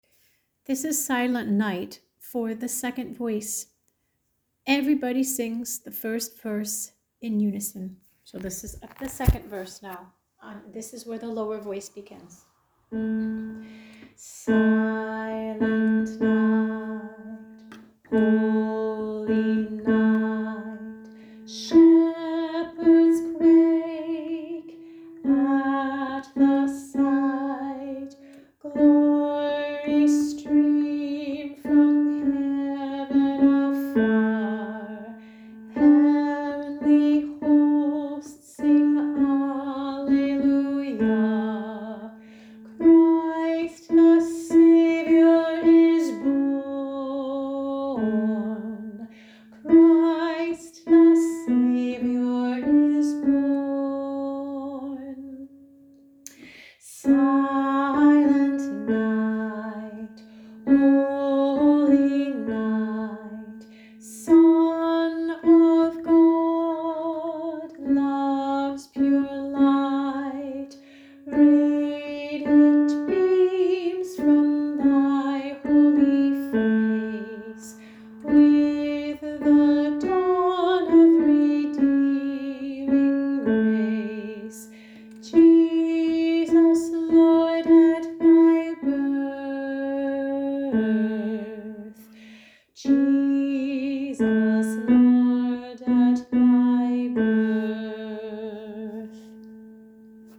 silent-night-1 Download Harmony sing along tracks Silent Night 2nd and 3rd v lower voice Silent Night main melody Share this: Share on Facebook (Opens in new window) Facebook Print (Opens in new window) Print Like Loading...
silent-night-2nd-and-3rd-v-lower-voice.m4a